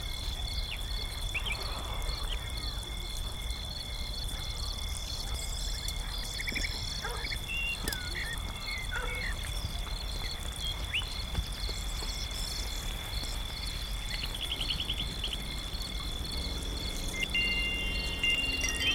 Ambiance Après-midi en ville (Broadcast) – Le Studio JeeeP Prod
Bruits d’ambiance d’un centre ville.